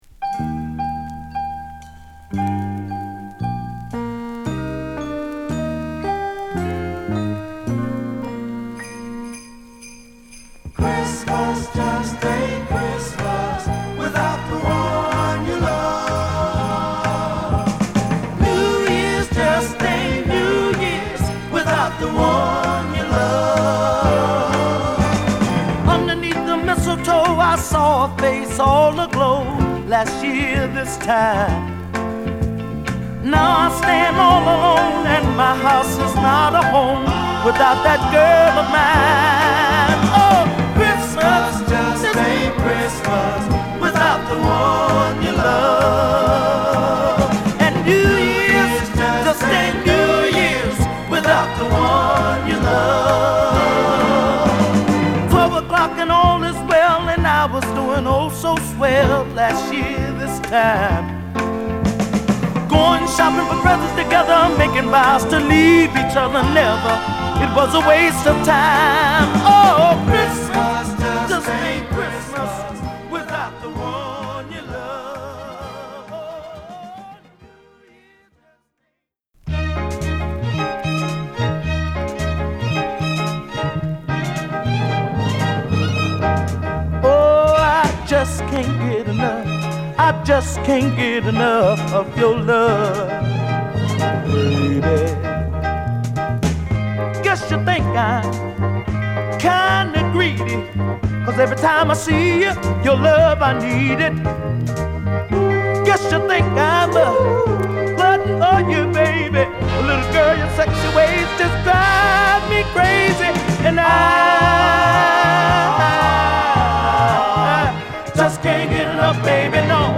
靭やかで高揚感のあるミディアムトラックのソウルフル・クリスマス！